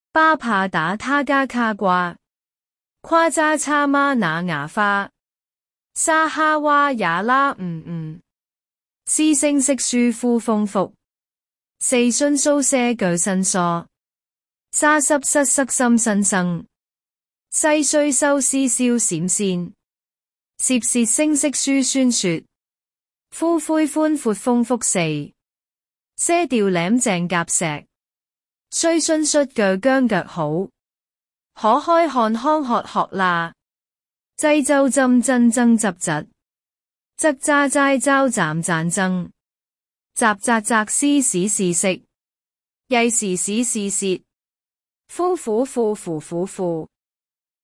116字学会粤拼-ttsmaker-菲儿.mp3